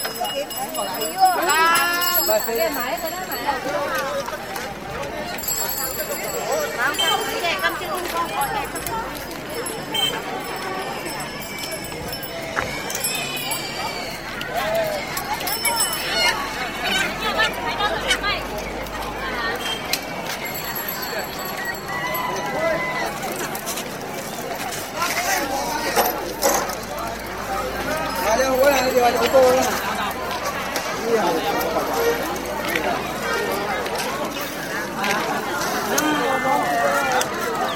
Urban atmospheres 2